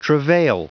Prononciation du mot travail en anglais (fichier audio)
Prononciation du mot : travail